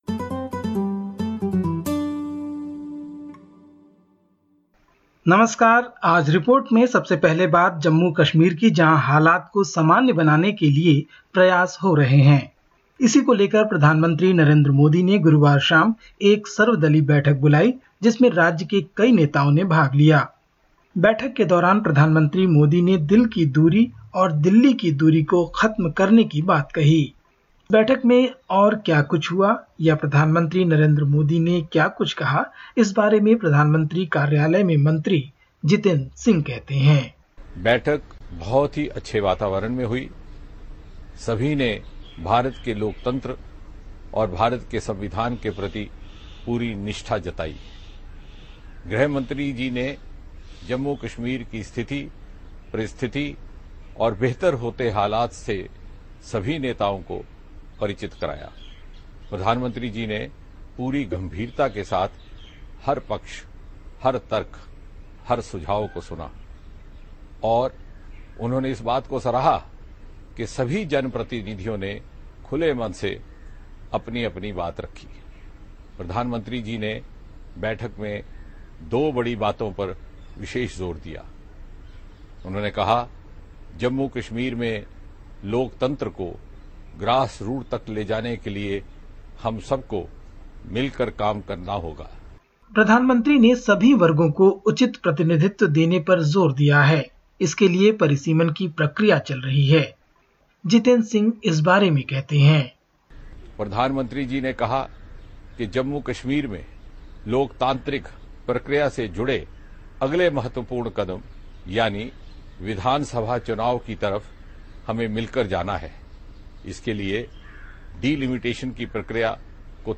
सुनिए भारत के हर छोटे-बड़े समाचार